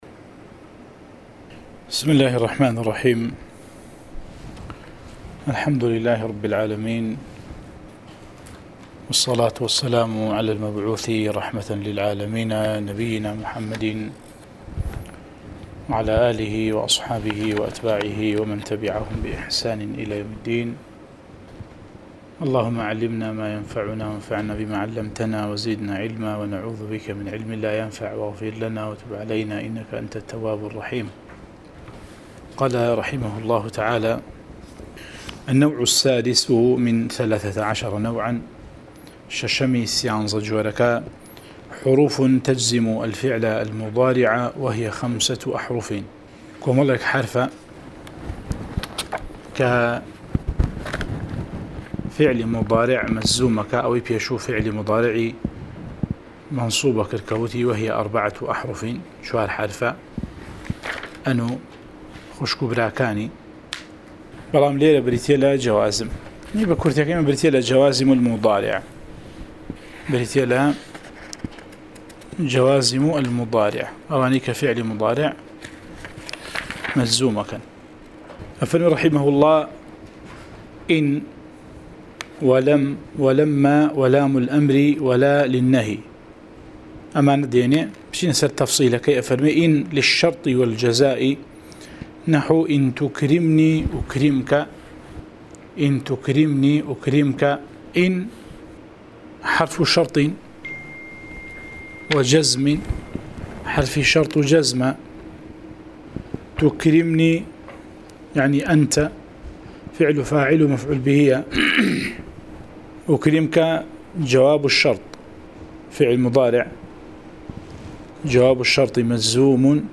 09 ـ شەرحی العوامل المائة، (عوامل الجرجانی) (نوێ) وانەی دەنگی: - شرح عوامل المائة (عوامل الجرجاني)